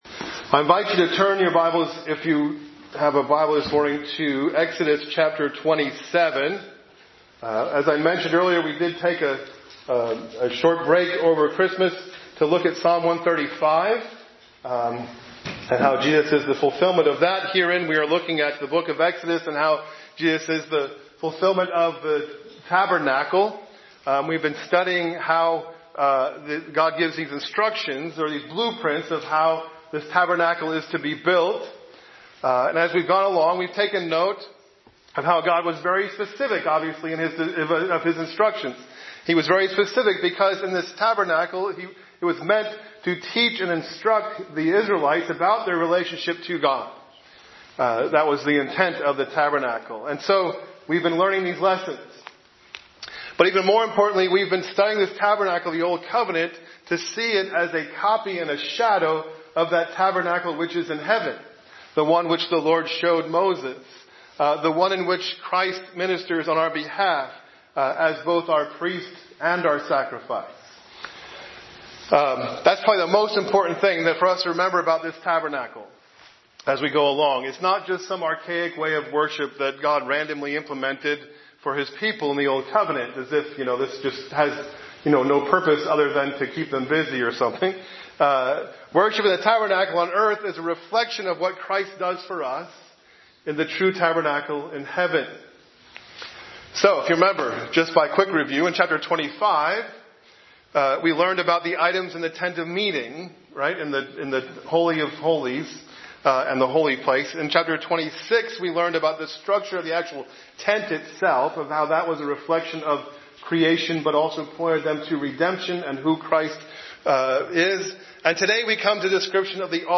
A message from the series "Exodus."